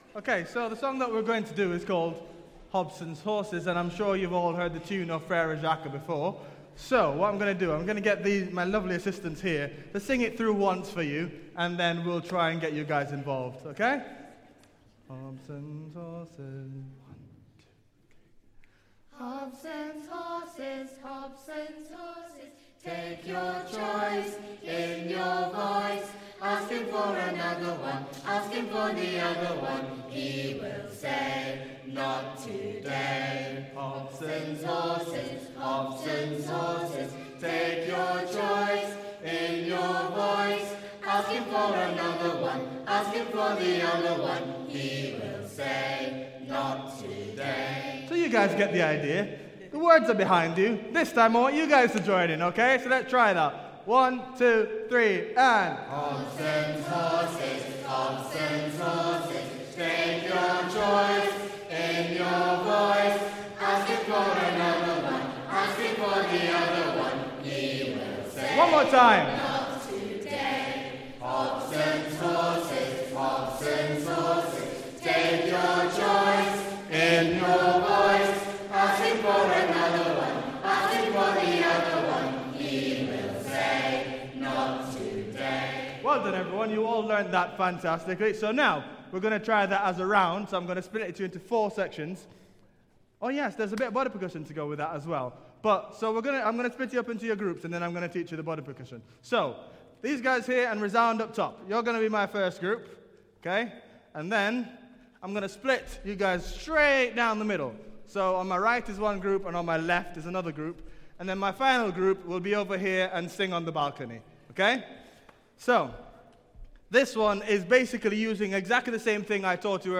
A round
concert